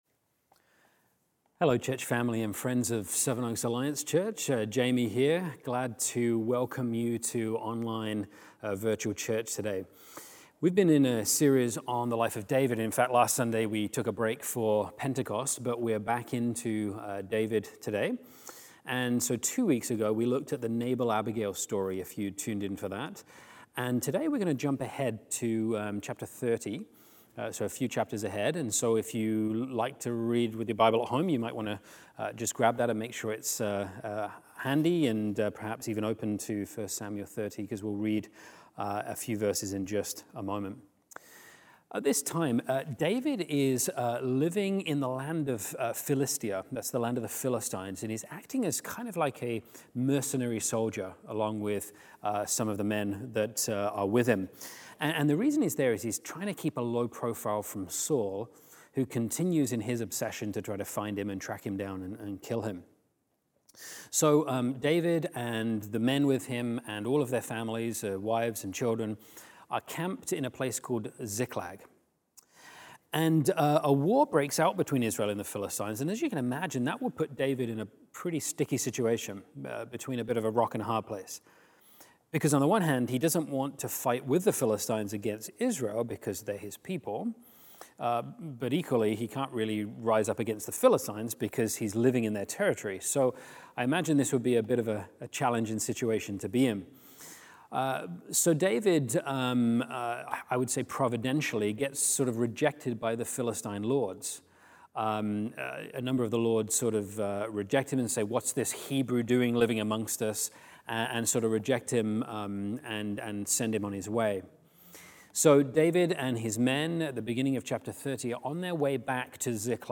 Sermons | Sevenoaks Alliance Church